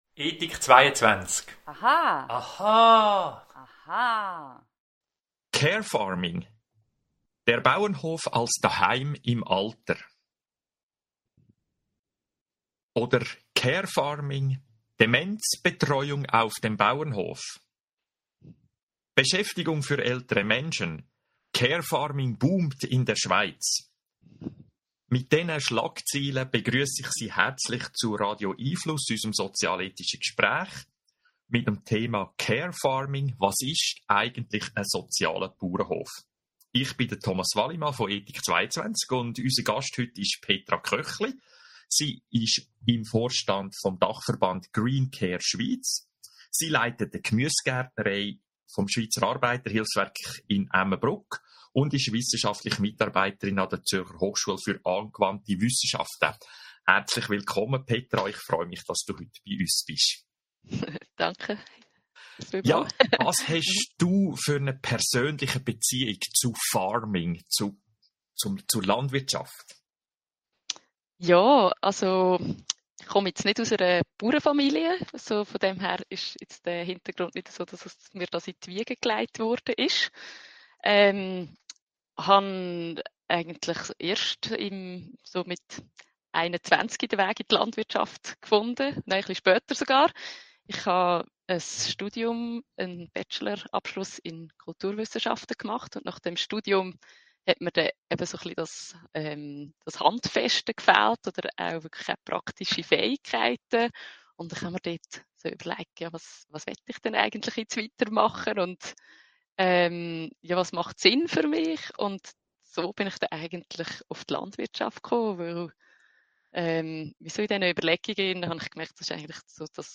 Dachverband Green Care Schweiz Podcast hier zuhören Radio🎙einFluss findet jeden Mittwoch 18:30 - 19 Uhr statt.